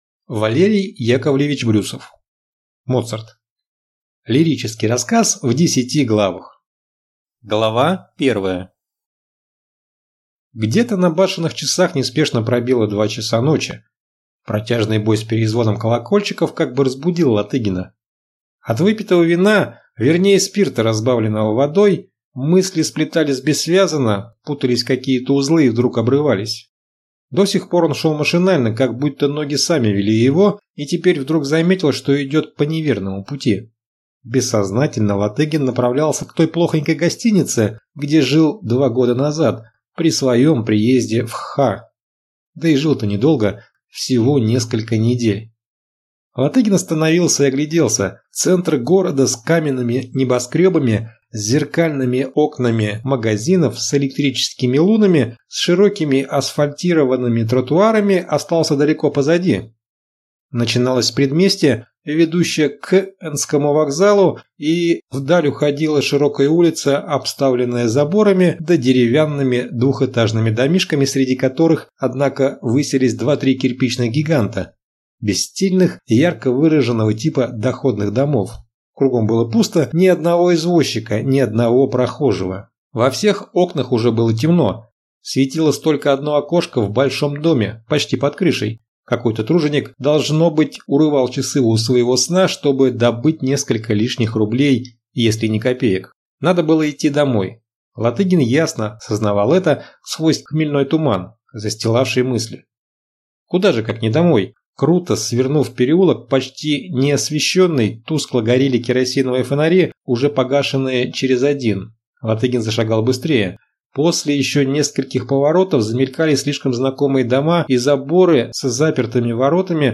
Аудиокнига Моцарт | Библиотека аудиокниг